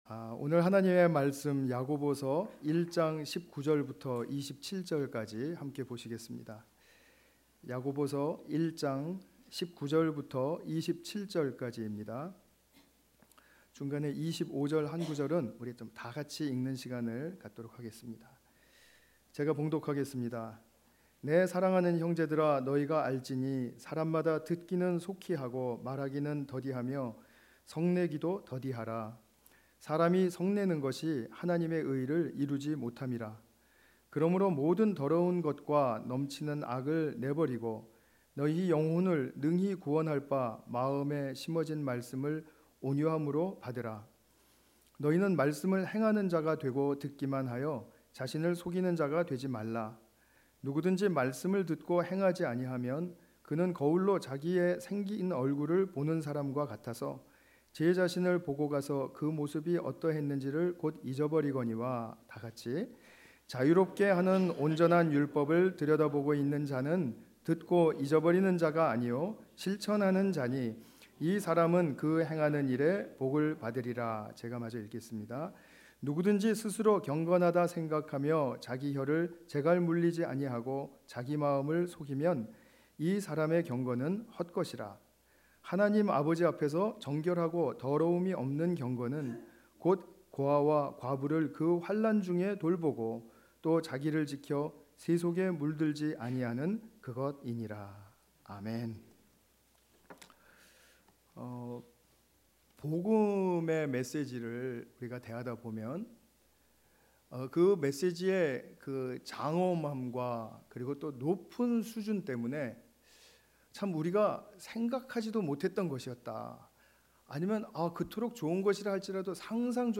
야고보서 1:19-27 관련 Tagged with 주일예배